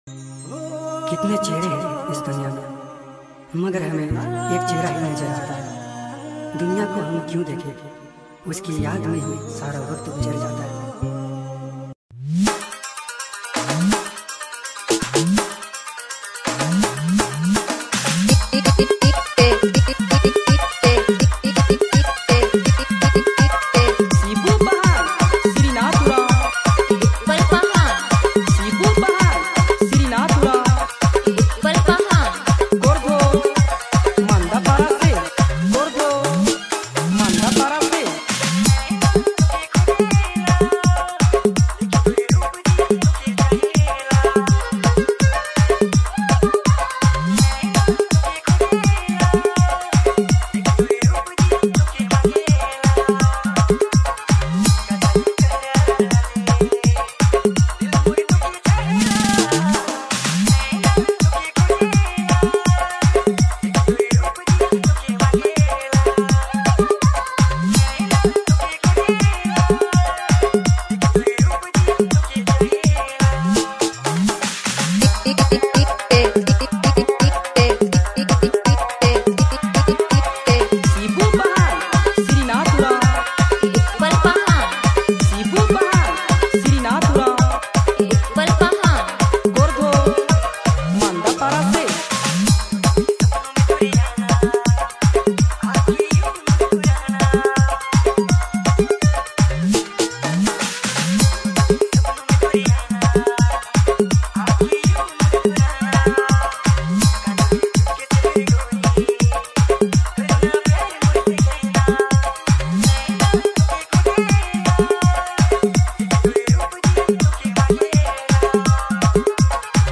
All Dj Remix
New Nagpuri Dj Song 2025